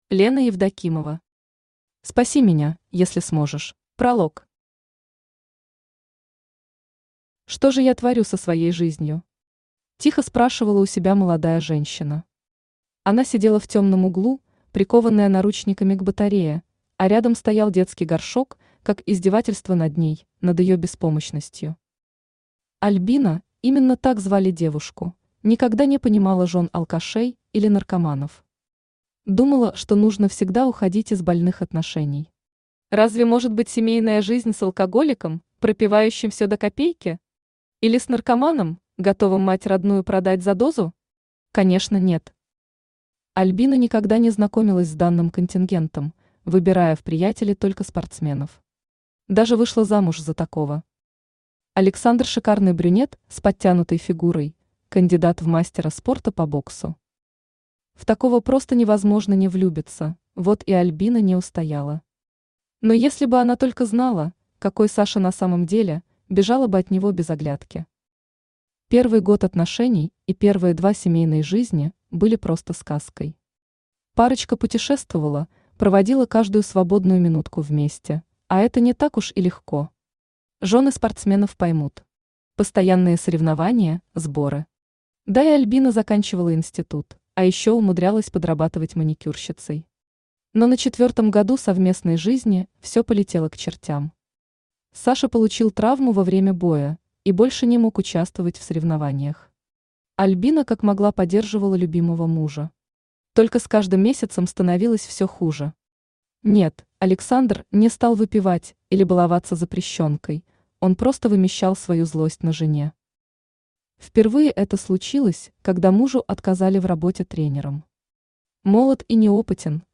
Аудиокнига Спаси меня, если сможешь | Библиотека аудиокниг
Aудиокнига Спаси меня, если сможешь Автор Лена Евдокимова Читает аудиокнигу Авточтец ЛитРес.